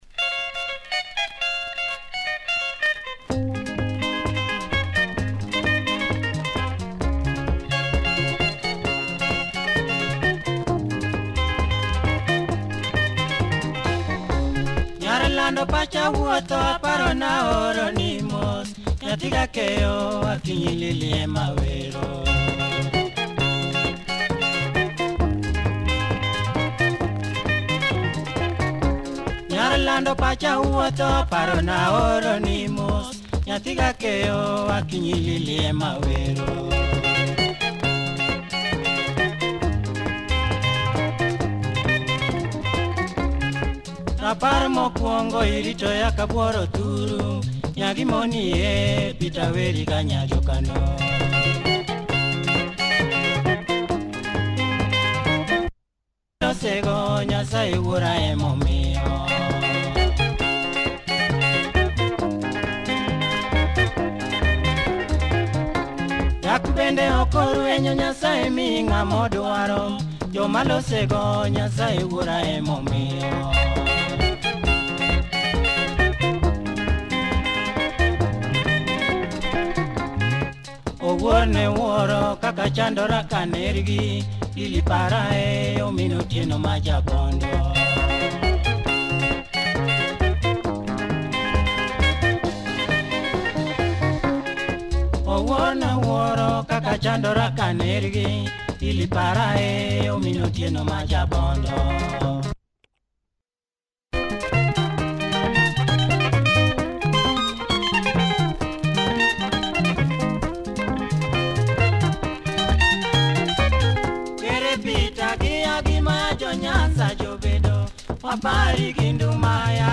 Nice party luo benga, good production, check audio! https